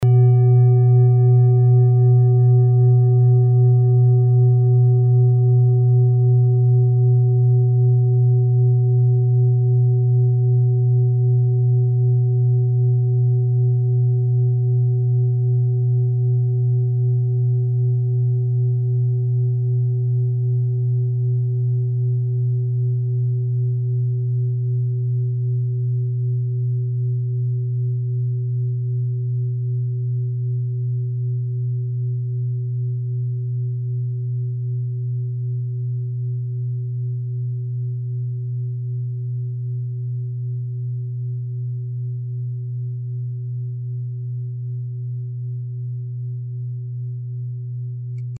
Klangschale Bengalen Nr.35
Klangschale-Gewicht: 1800g
Klangschale-Durchmesser: 26,1cm
Sie ist neu und wurde gezielt nach altem 7-Metalle-Rezept in Handarbeit gezogen und gehämmert.
klangschale-ladakh-35.mp3